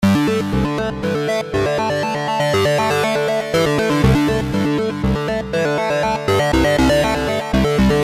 描述：padloop experiment c 80bpm
标签： 合成器 循环 雄蜂环 环境
声道立体声